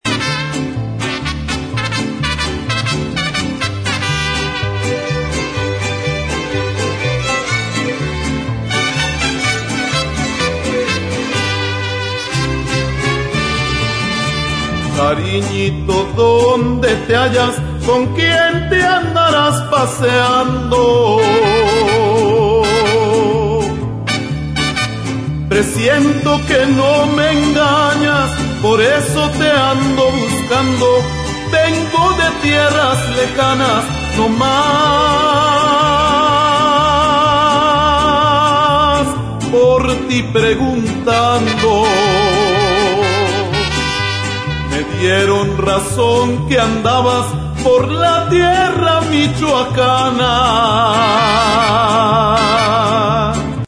Sample demo 1